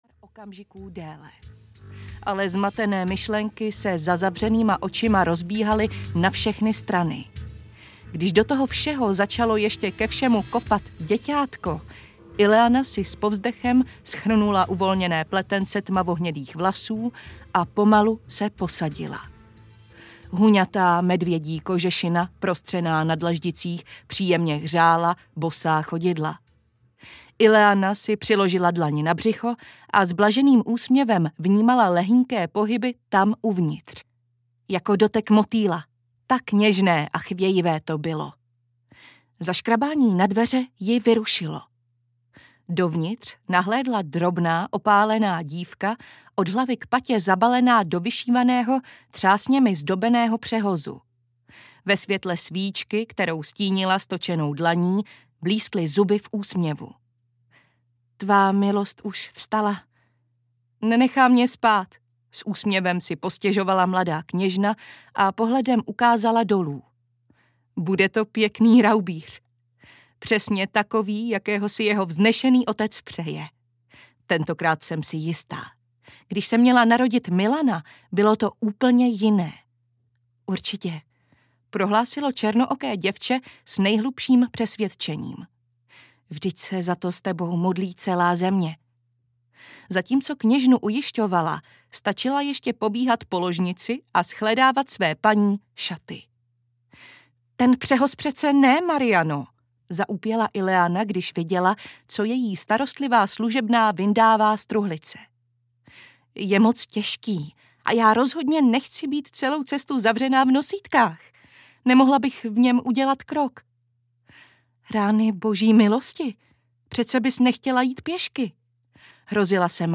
Dračí krev audiokniha
Ukázka z knihy